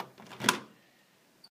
doors_wood2.ogg